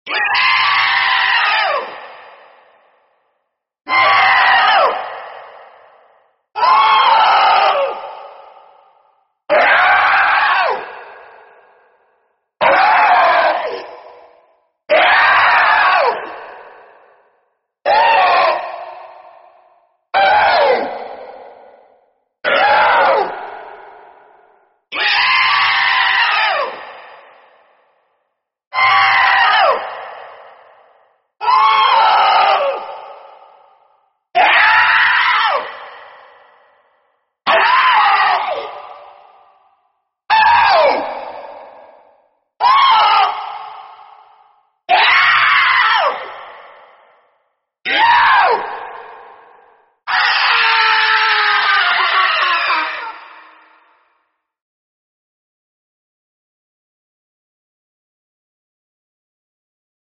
Bloodborne Orphan of Kos bossfight screams
orphan-of-kos-screams.mp3